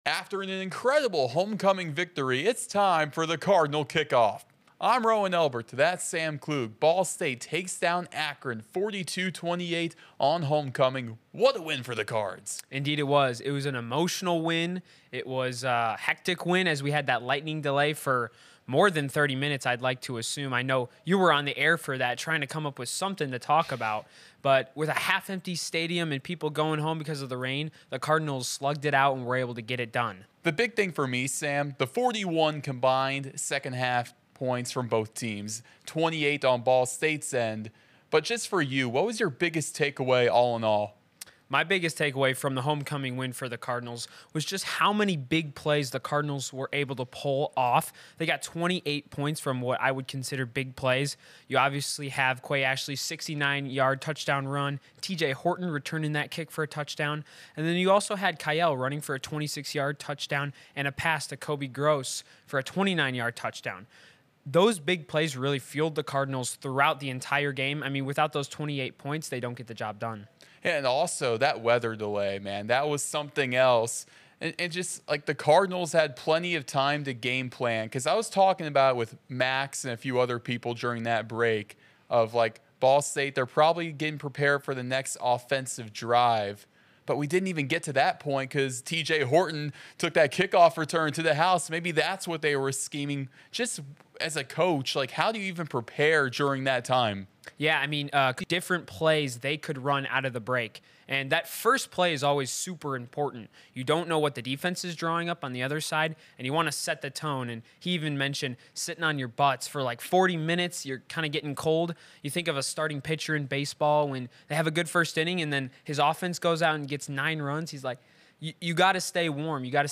Each week go inside Ball State Football with exclusive interviews, feature stories, and discussion.